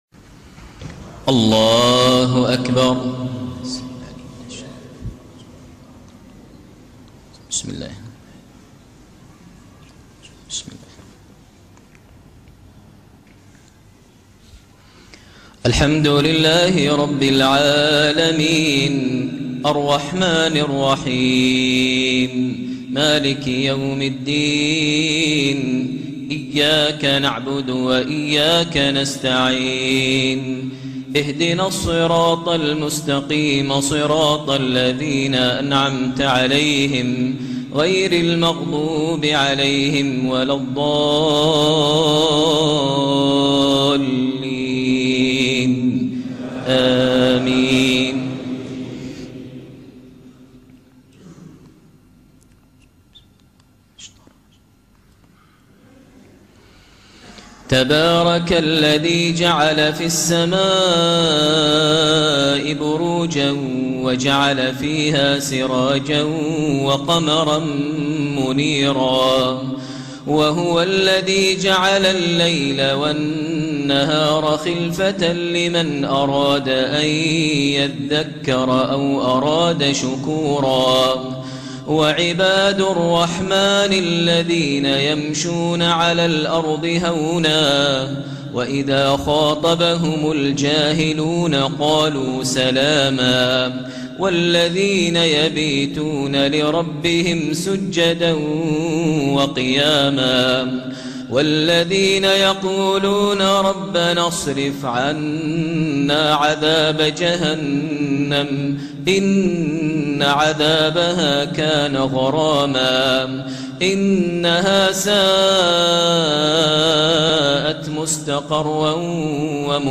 صلاة العشاء من مسجد الشيخ زايد بأم القوين لخواتيم سورة الفرقان > زيارة الشيخ ماهر المعيقلي لدولة الإمارات ١٤٣٥هـ > المزيد - تلاوات ماهر المعيقلي